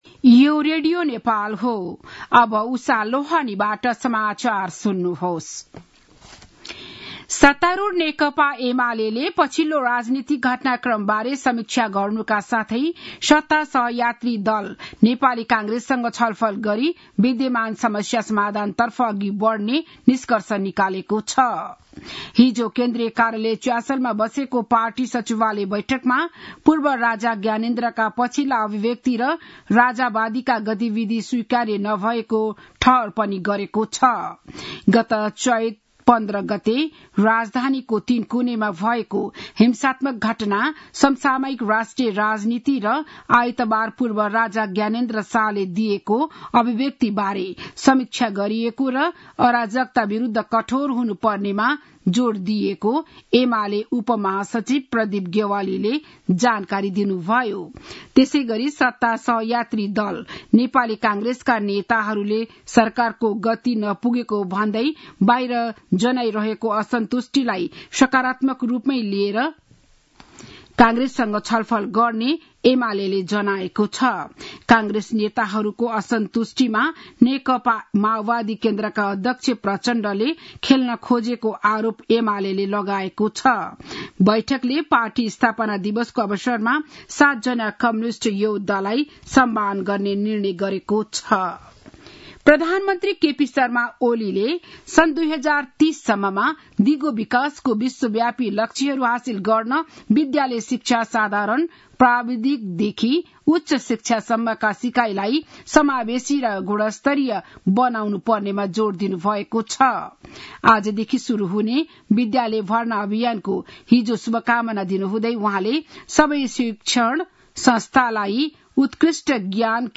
बिहान ११ बजेको नेपाली समाचार : २ वैशाख , २०८२
11-am-news-1-5.mp3